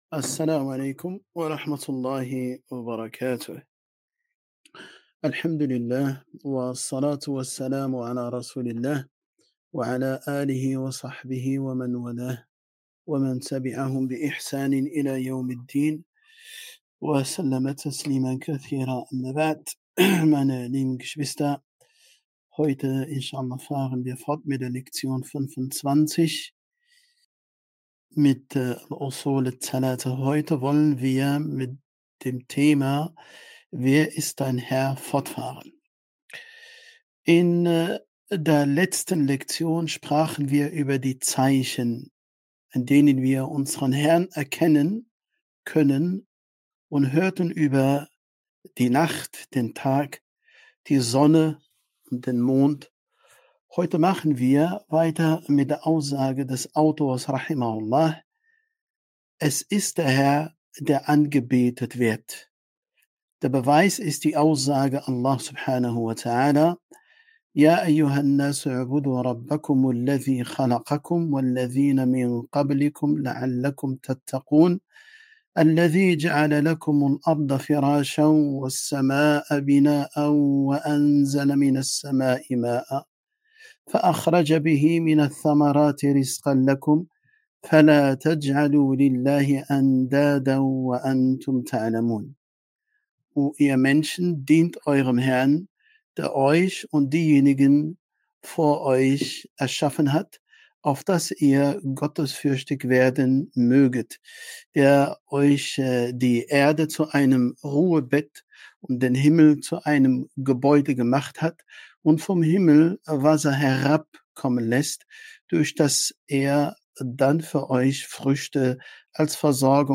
*Der Unterricht findet jeden Donnerstag online statt.